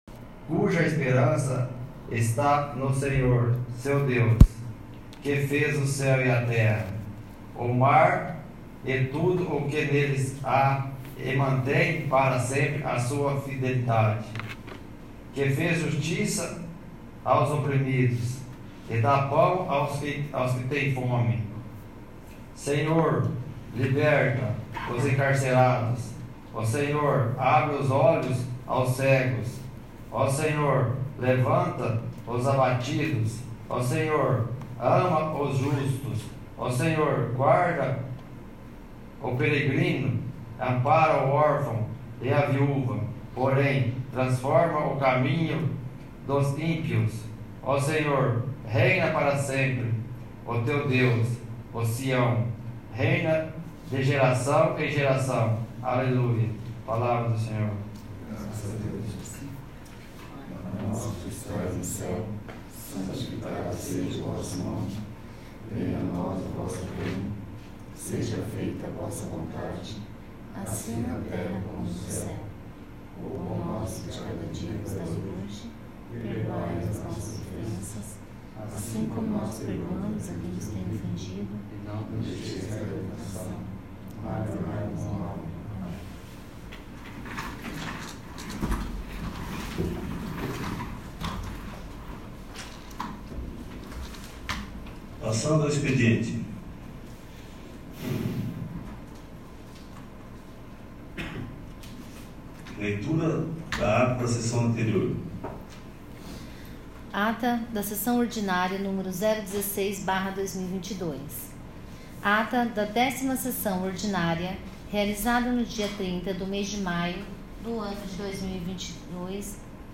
17º. Sessão Ordinária